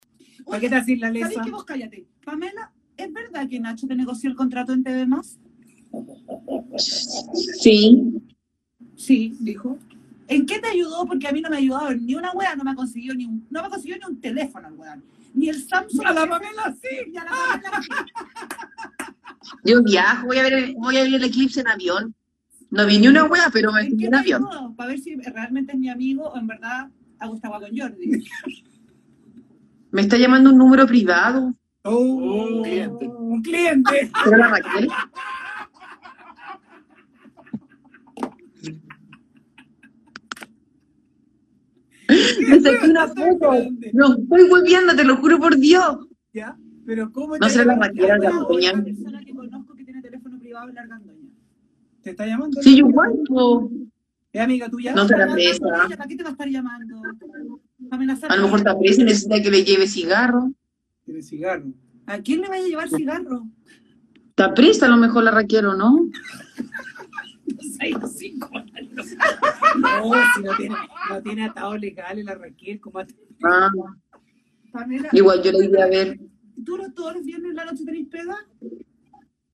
Resulta que estaban comentando un tema y, en ese momento comenzó a sonar el teléfono de la pareja de Jean Philippe.